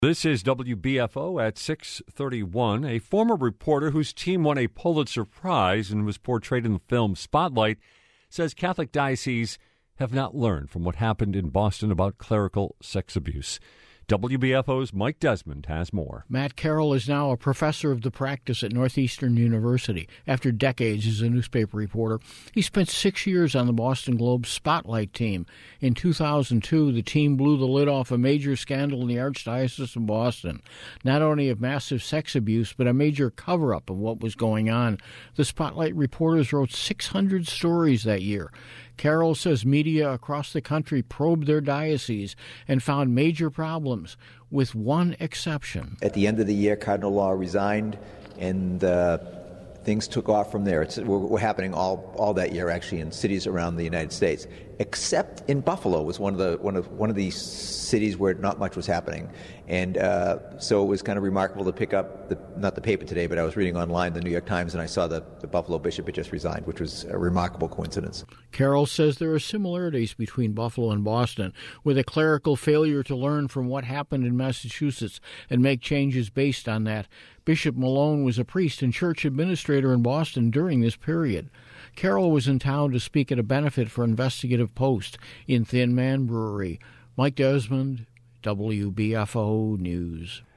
WBFO story